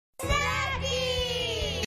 snackeez-tv-commercial-snacking-solution-online-video-cutter.mp3